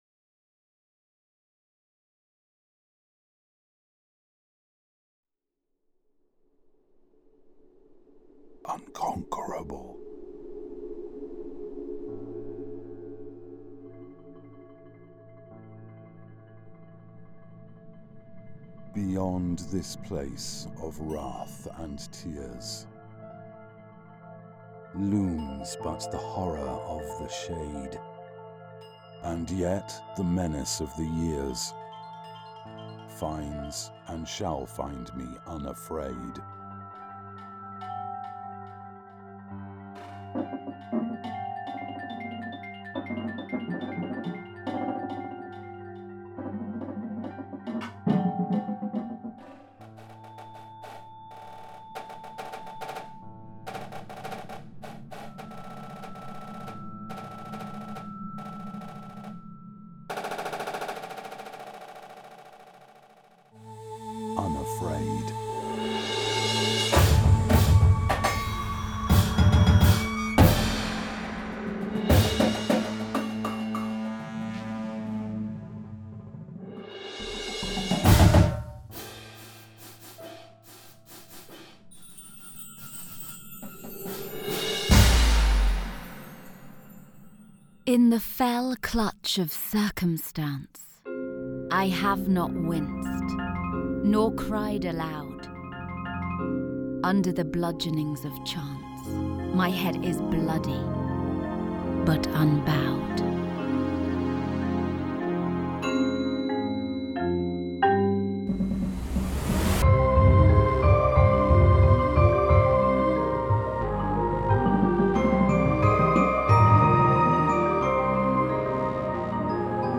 This show is scored for large ensembles.